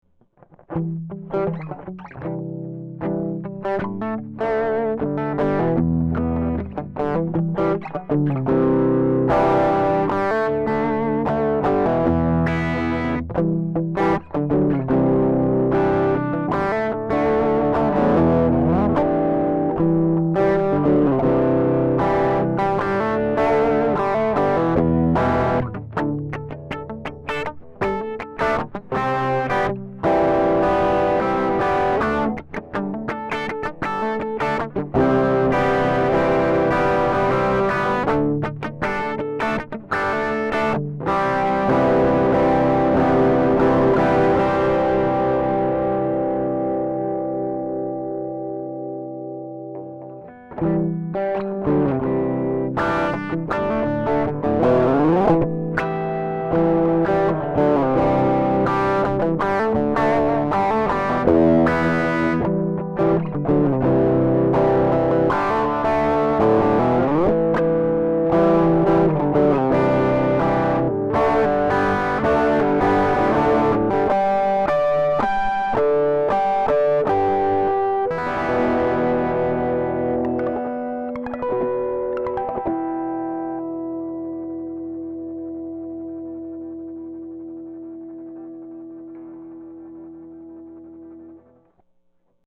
Here are 7 quick, 1-take MP3 sound files of myself playing this guitar to give you an idea of what to expect. The guitar has great tone, sustain, and easy playability, and also excellent tonal variety. The guitar is tracked using only a TAB-Funkenwerk V71 Tube Direct Box on the clean sounds, and the distorted sounds are a Doc Scary SCARY DRIVE pedal going into the DI, and MP3s were made in Logic.
345V71Harmonic.mp3